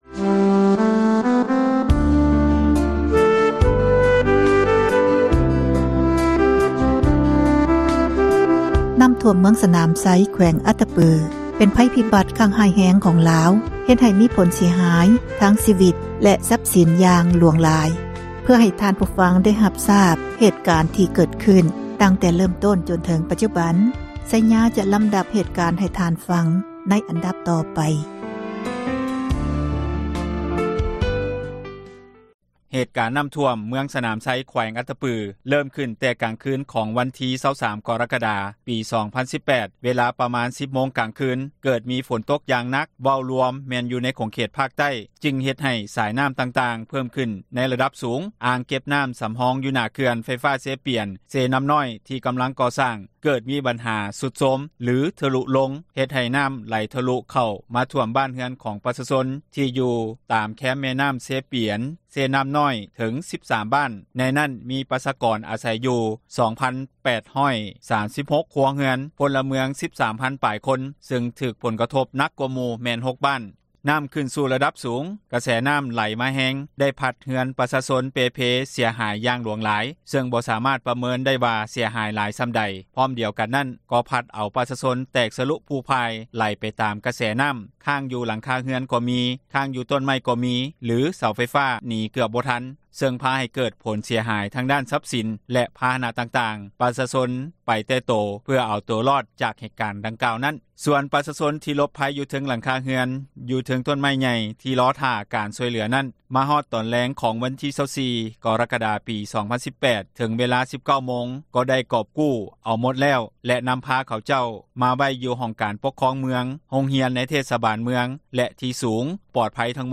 ຕໍ່ກັບກໍຣະນີ ການເກັບກູ້ເອົາຜູ້ຍັງມີຊິວິດຢູ່ ຕລອດສອງວັນຜ່ານ ທ່ານ ອຸ່ນຫລ້າ ໄຊຍະສິດ ຮອງເຈົ້າແຂວງ ອັດຕະປື ທັງເປັນຮອງປະທານ ຄນະກັມມມະການຄຸ້ມຄອງ ພັຍພິບັດ ກ່າວວ່າ: